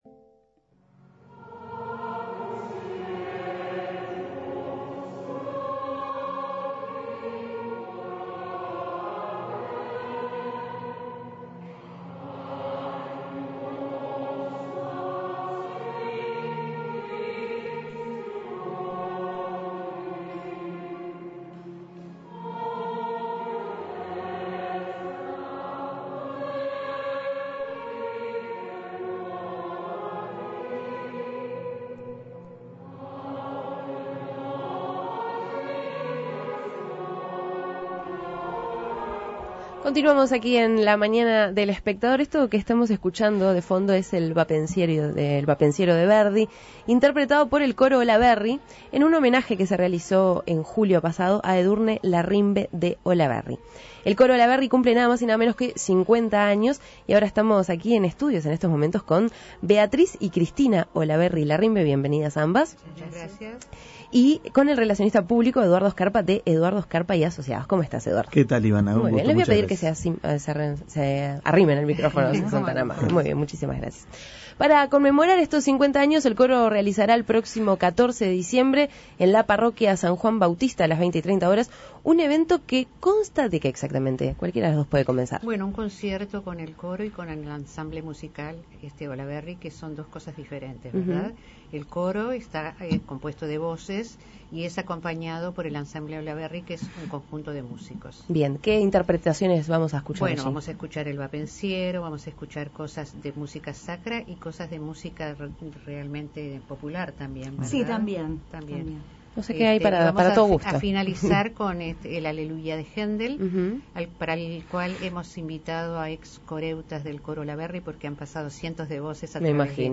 En nuestros estudios estuvieron presentes